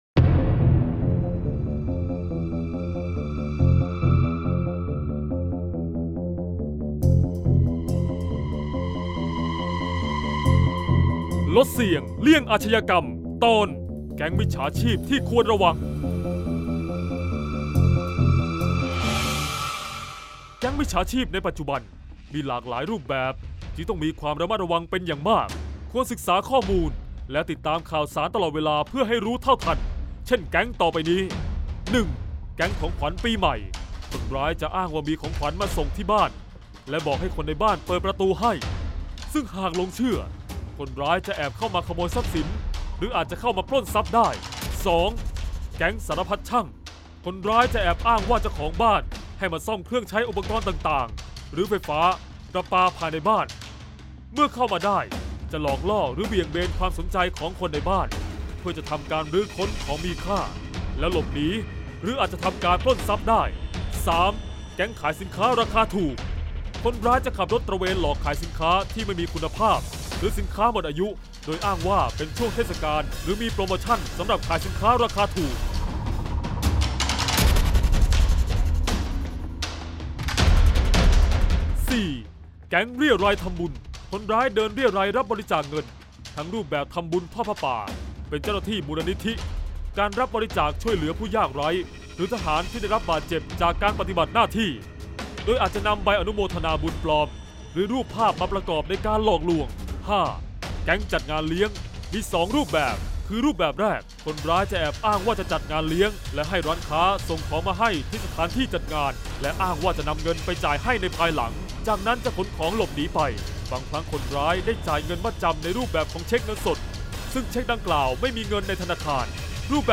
เสียงบรรยาย ลดเสี่ยงเลี่ยงอาชญากรรม 30-แก๊งมิชฉาชีพที่ควรระวัง